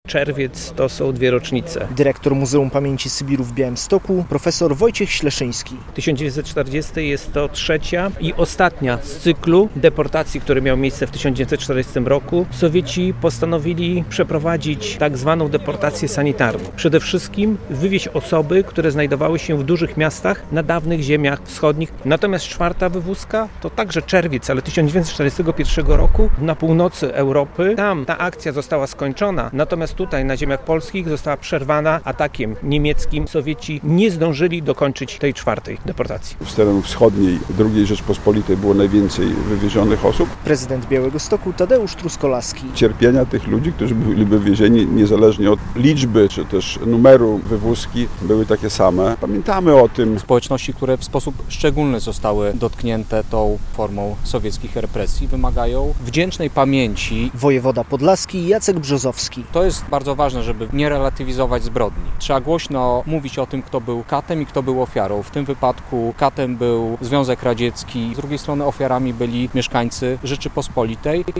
Władze państwowe i samorządowe, działacze Związku Sybiraków, przedstawiciele służb mundurowych upamiętnili w sobotę (21.06) w Białymstoku ofiary dwóch masowych deportacji obywateli polskich na Wschód, dokonane przez Sowietów w czerwcu 1940 i 1941 roku. Wieńce i kwiaty składano przy pomniku - Grobie Nieznanego Sybiraka.
relacja